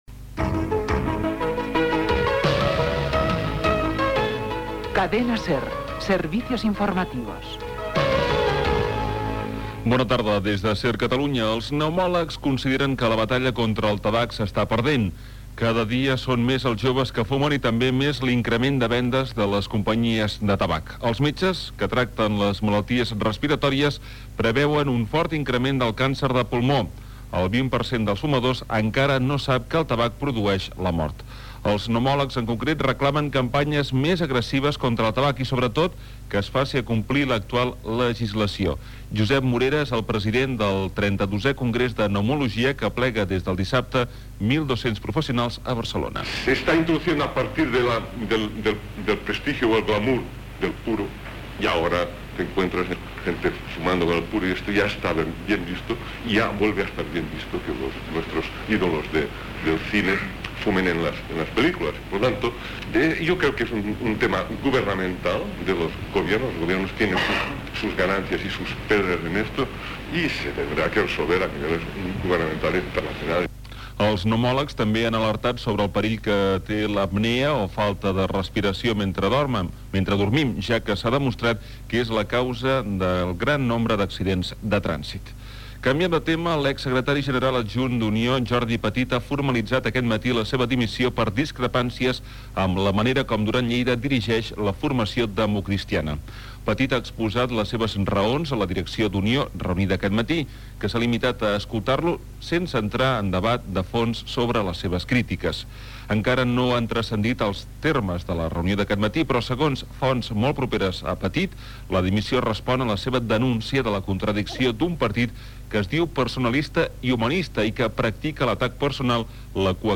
Informatiu